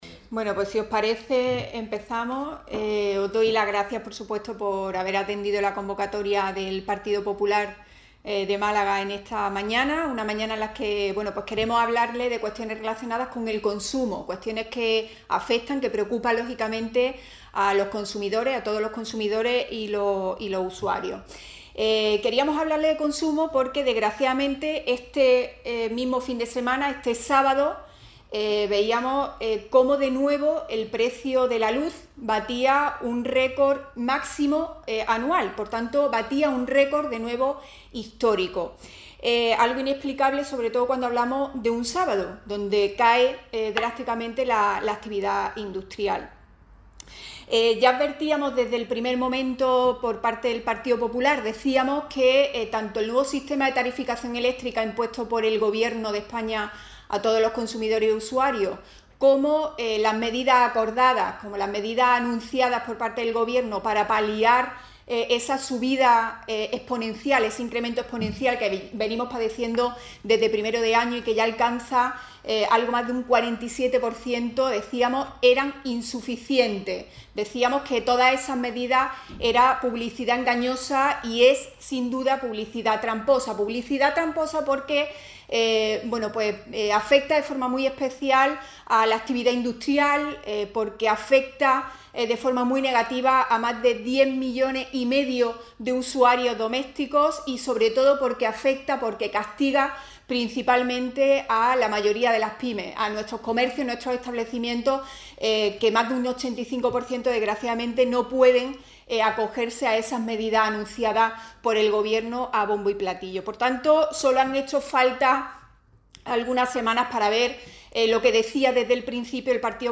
Así lo ha expuesto en rueda de prensa, destacando que “la hoja de servicio de Garzón es atacar al turismo, subirnos la luz hasta máximos históricos y asfixiar a los empresarios, que se ven especialmente perjudicados por esta falta de respuesta del Gobierno de coalición, el más caro de la historia”.